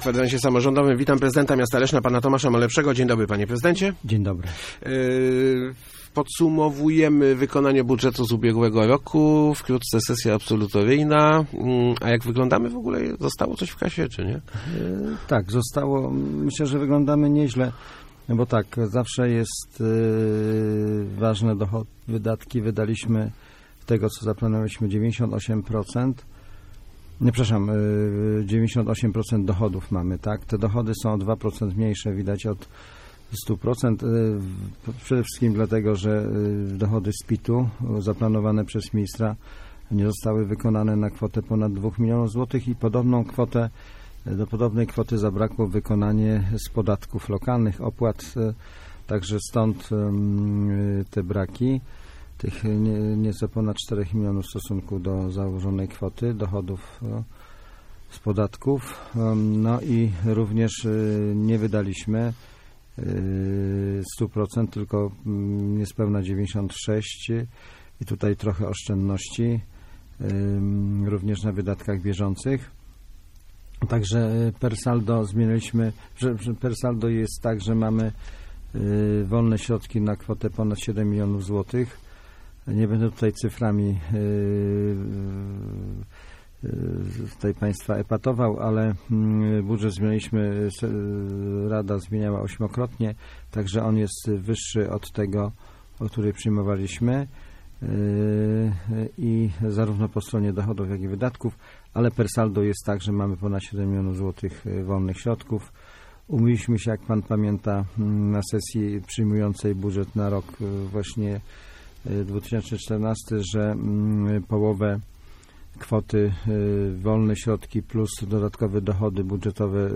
Gościem Kwadransa był prezydent Tomasz Malepszy. ...